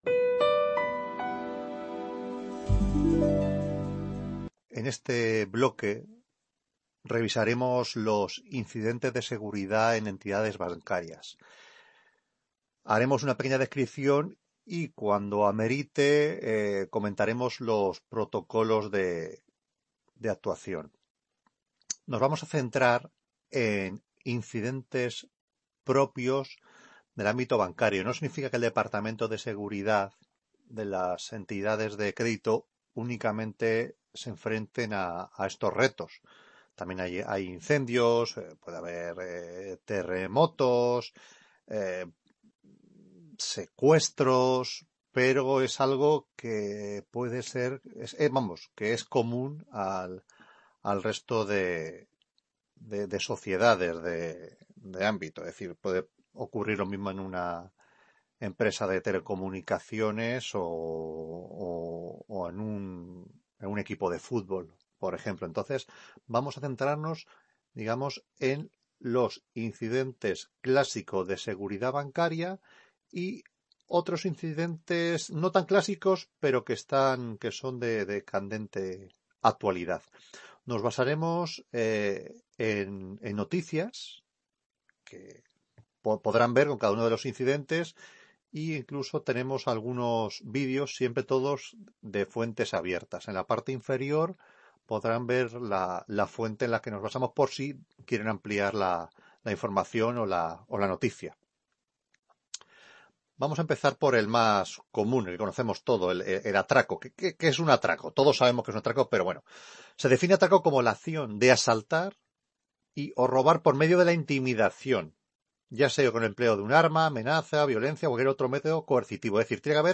Ponencia sobre Seguridad en Entidades de Crédito - 2ª…